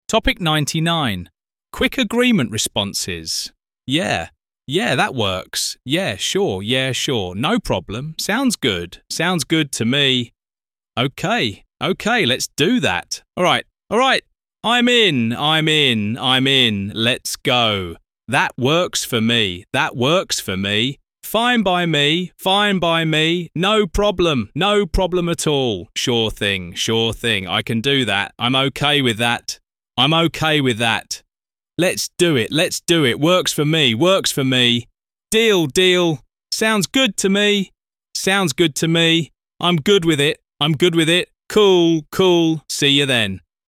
Mỗi từ vựng đều đi kèm câu ví dụ thực tế, được đọc rõ ràng trong file MP3, giúp bạn hiểu và nhớ nhanh từ và vị trí của từ trong câu, biết dùng từ đó ở đâu – dùng như thế nào cho đúng.